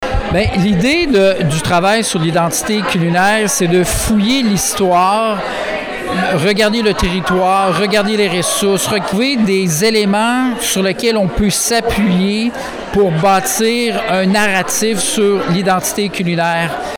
Cette étude a été présentée mercredi en conférence de presse.